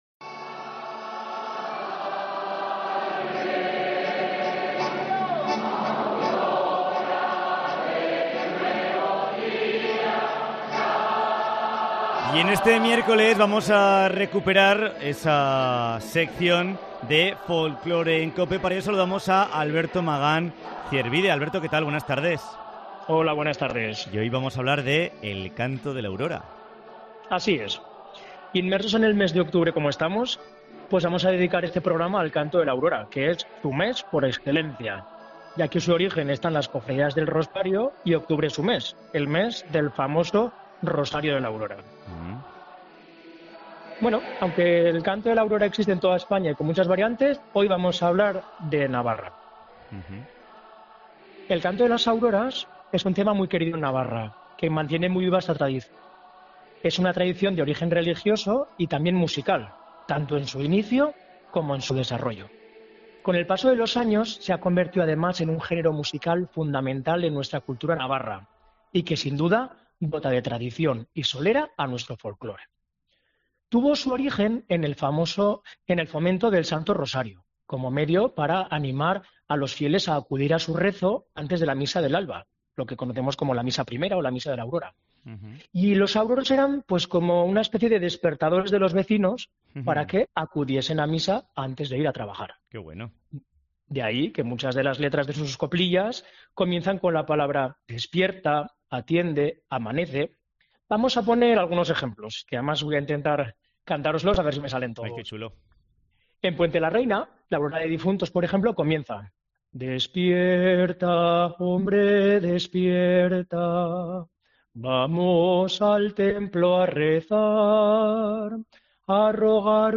(Voy a intentar cantároslos, a ver si me salen).